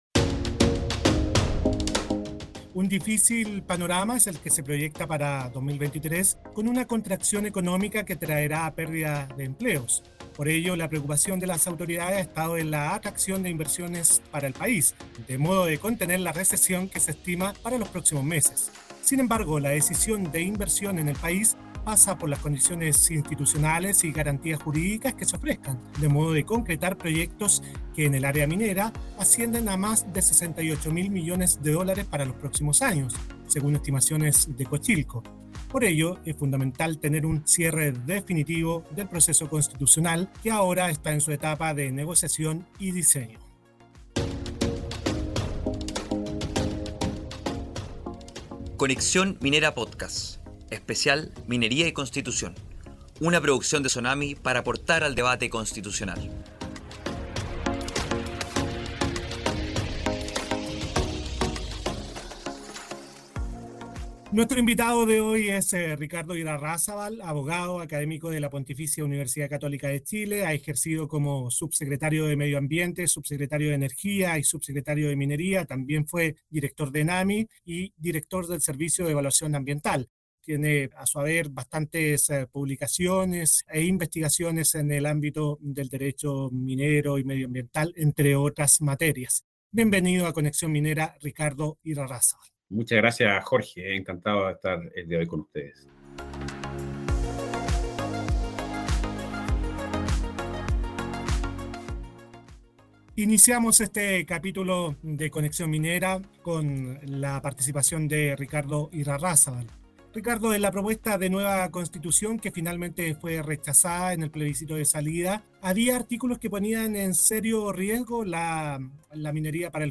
Como parte del debate constitucional, SONAMI ha comenzado una serie de podcasts en que se entrevistan a diversos personeros del ámbito minero y otros sectores para analizar el borrador y la futura nueva Constitución, que será sometida a plebiscito el 4 de septiembre.